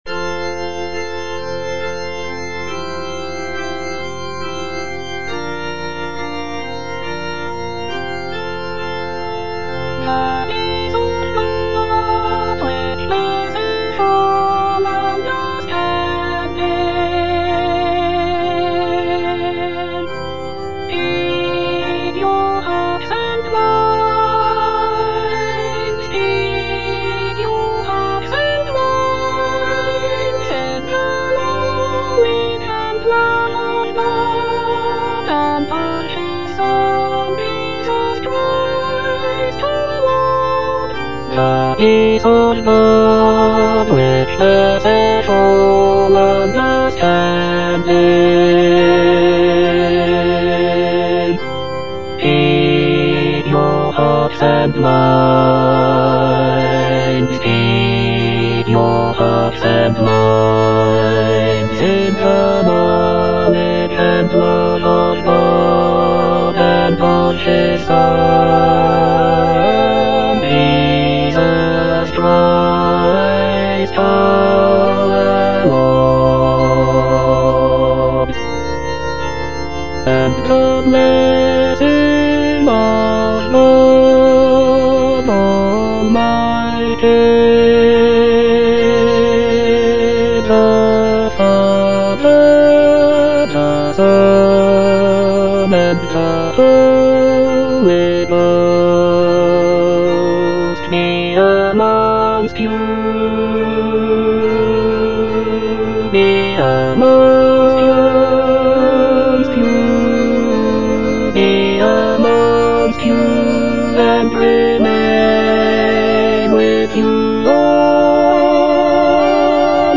Bass (Emphasised voice and other voices)